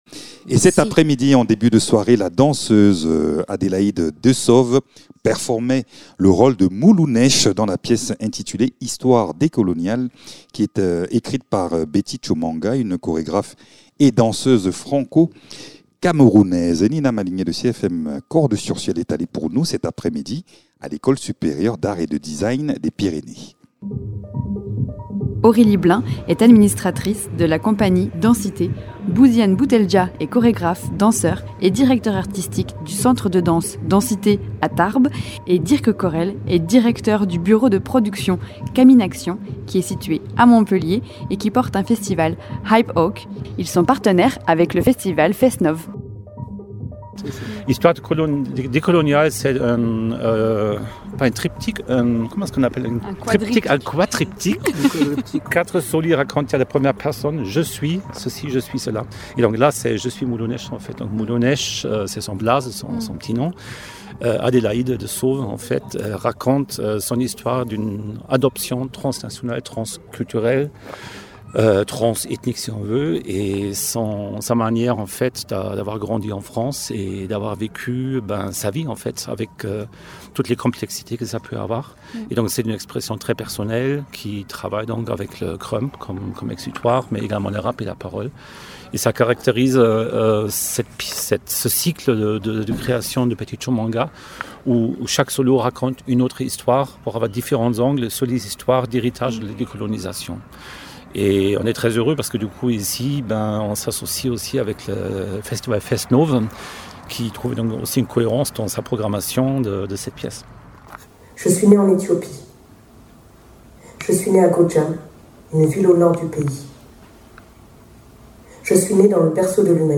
Avec Histoires décoloniales, la danse devient un langage puissant pour revisiter l’histoire et explorer les mémoires oubliées. Le spectacle mêle corps, mouvement et émotion pour offrir un regard engagé et sensible sur des récits souvent tus. Dans ce reportage, plongez au cœur d’une création qui interroge, bouleverse et invite à la réflexion collective.